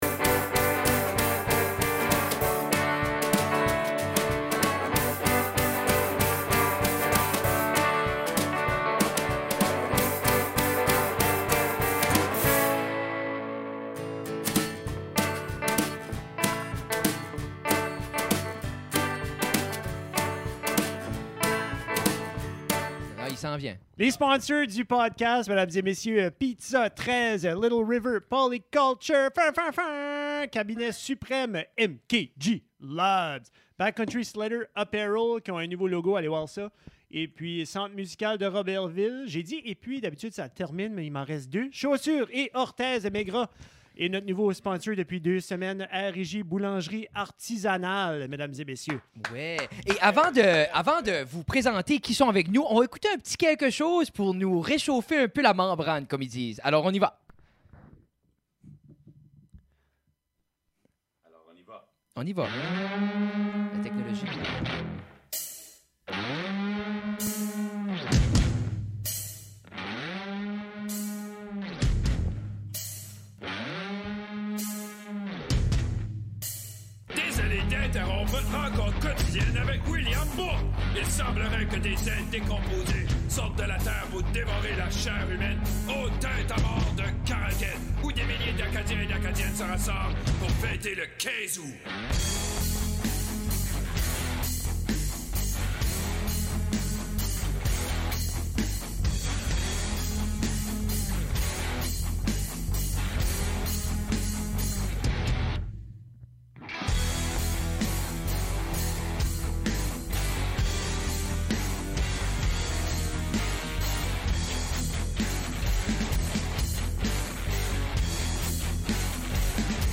deux acadiens dans une cave qui jasent de ce qui se passe dans leur vie.